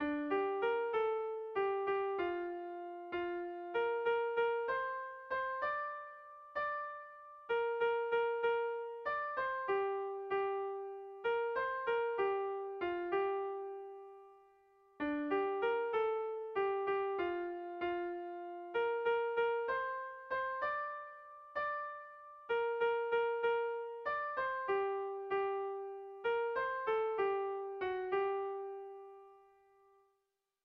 Sehaskakoa
ABAB2